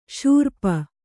♪ śurpa